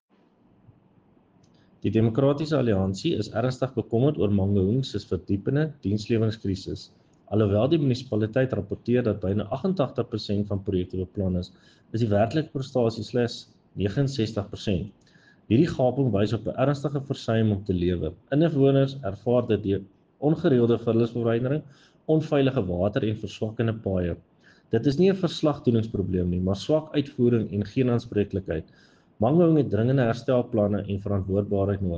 Afrikaans soundbites by Cllr Jan-Hendrik Cronje and Sesotho soundbite by David Masoeu MPL.